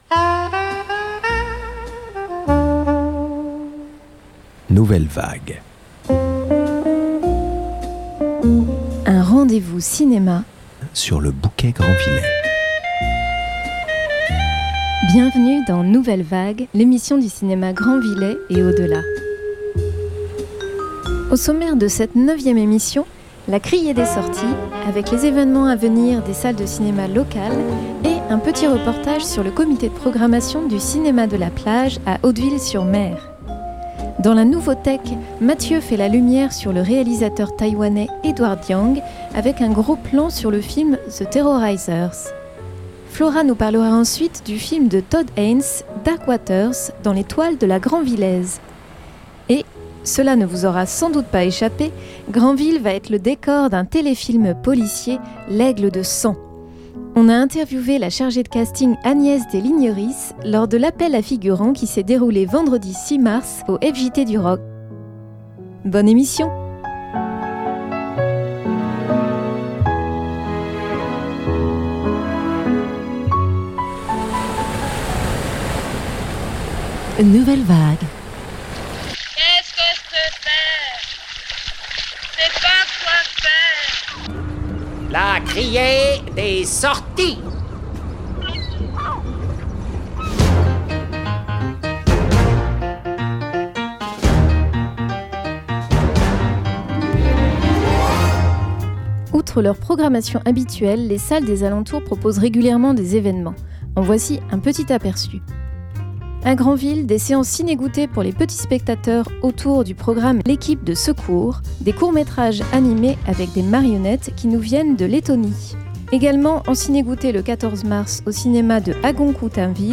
La criée des sorties présente les évènements à venir des salles de cinéma locales et vous propose pour ce 9ème numéro, un reportage sur le comité de programmation du cinéma de la plage, à Hauteville-sur-mer.